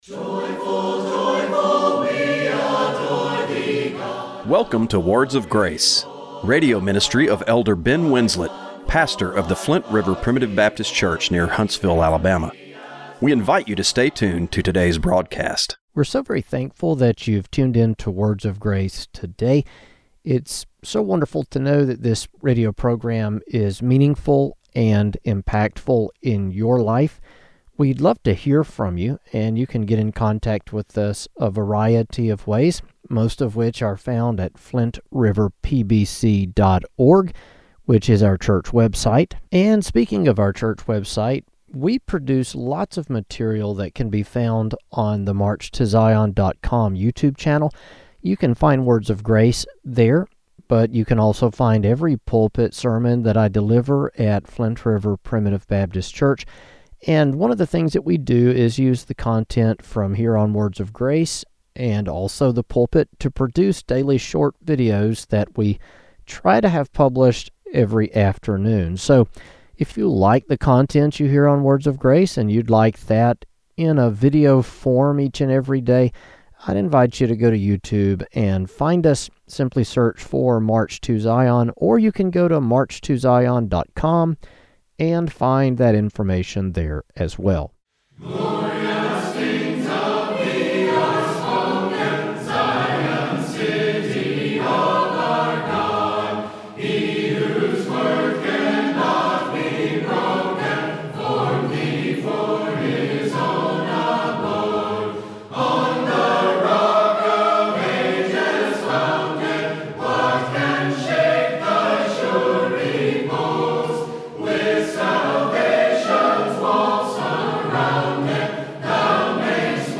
Radio broadcast for February 8, 2026.